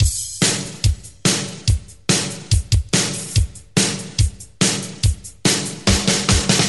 143 Bpm Old School Breakbeat Sample F# Key.wav .WAV .MP3 .OGG 0:00 / 0:07 Free drum loop - kick tuned to the F# note.
143-bpm-old-school-breakbeat-sample-f-sharp-key-k6o.wav